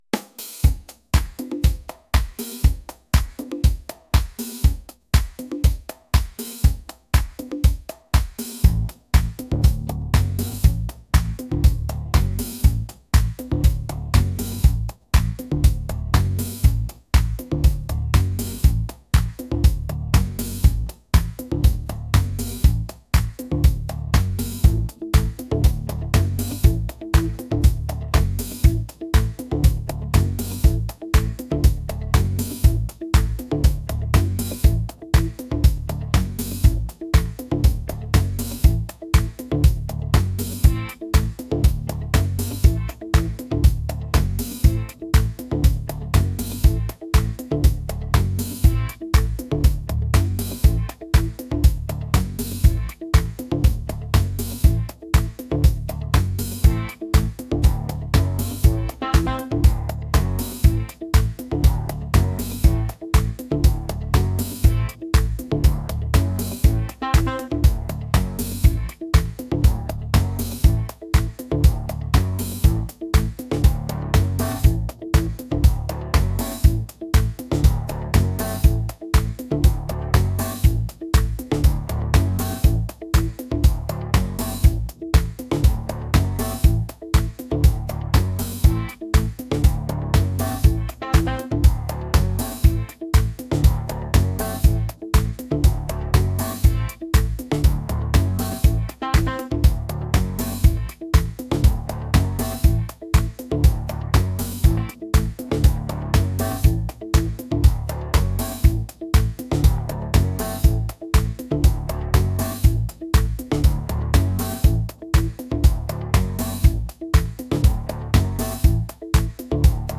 funky